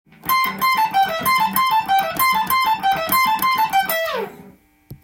オリジナルギターtab譜　key Am
フレーズは全てペンタトニックスケールで構成されています。